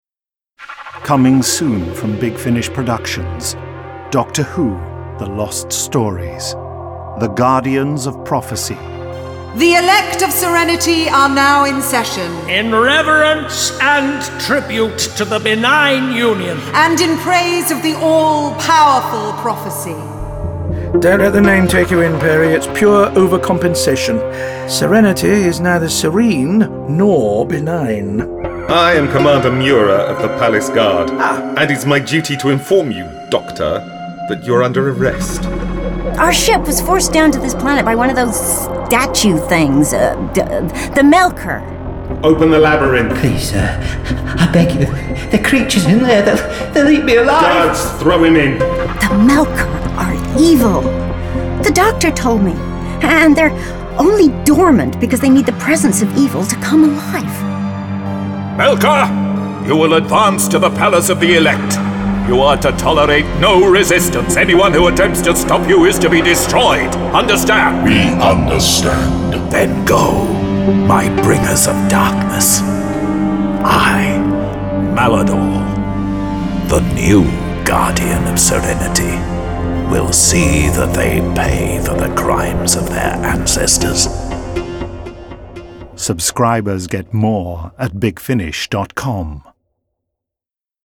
Award-winning, full-cast original audio dramas from the worlds of Doctor Who
Starring Colin Baker Nicola Bryant